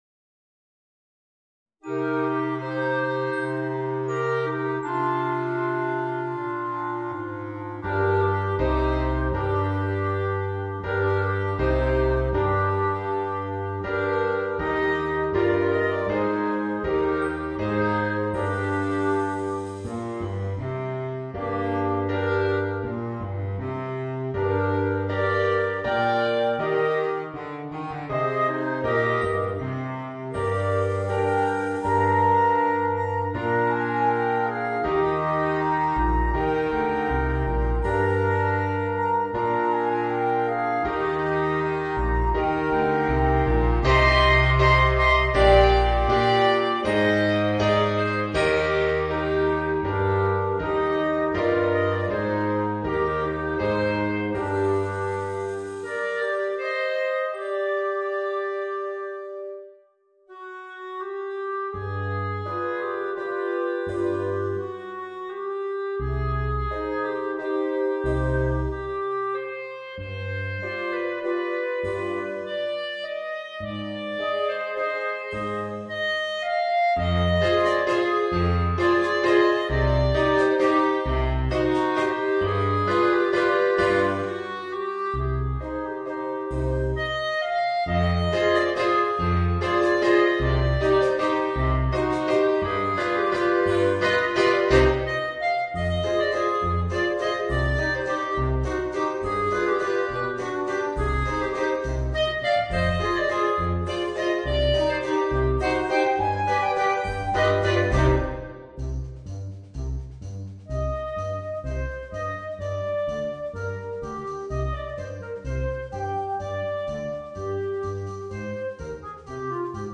Voicing: 4 Clarinets